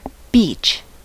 Ääntäminen
IPA : /biːt͡ʃ/